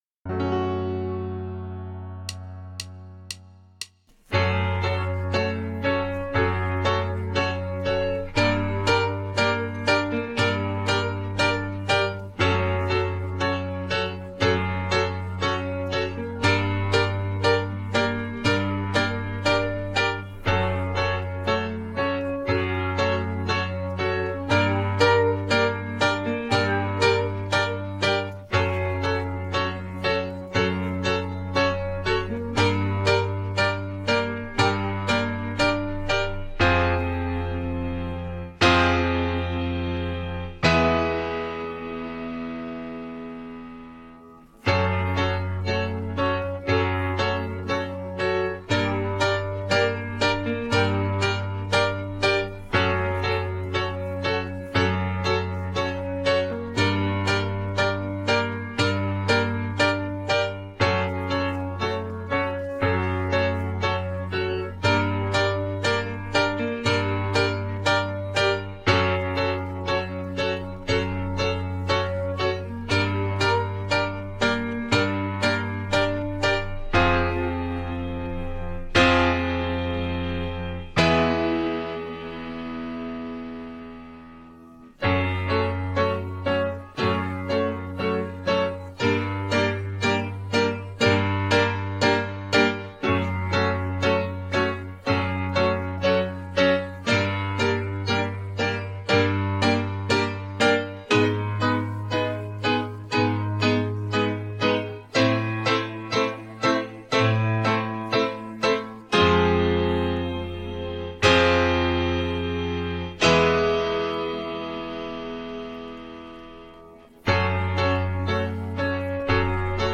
Übungsaufnahmen - Immer
Immer (Playback)